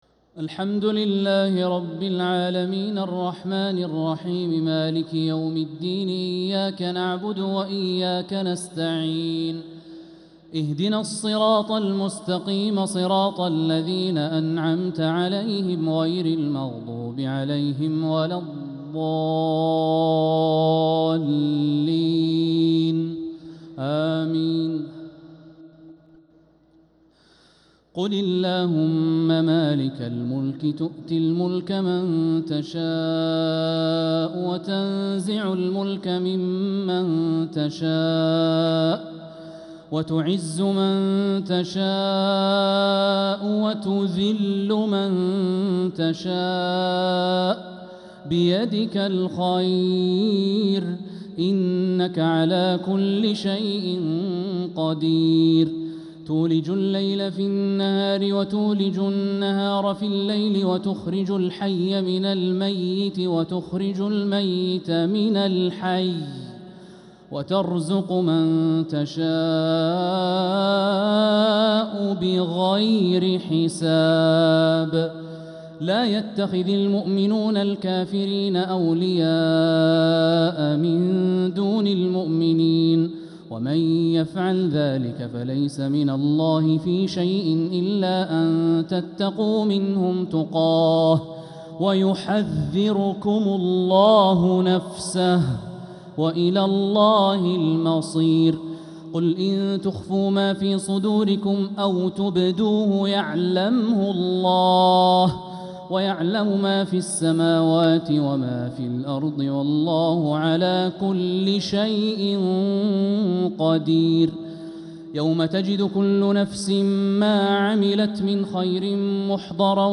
تراويح ليلة 4 رمضان 1446هـ من سورة آل عمران {26-74} | Taraweeh 4th night Ramadan 1446H Surat Aal-i-Imraan > تراويح الحرم المكي عام 1446 🕋 > التراويح - تلاوات الحرمين